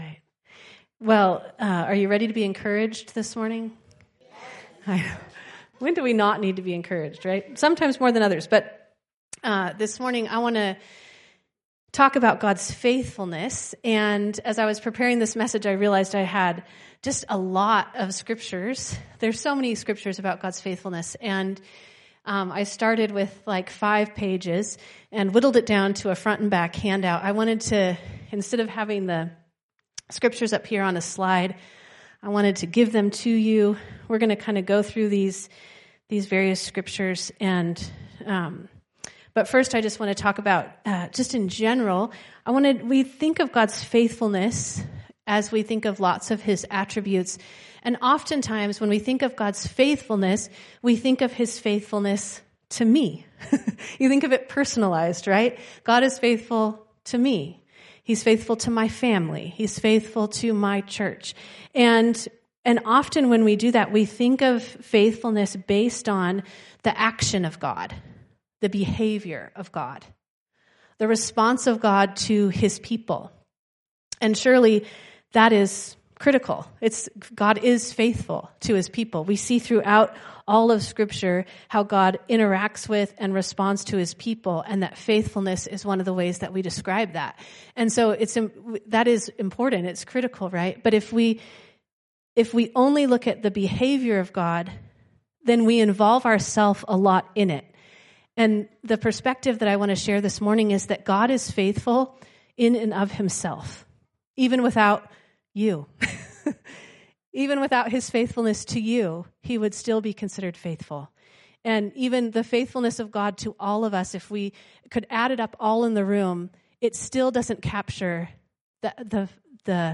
sharing at Grace on July 30, 2023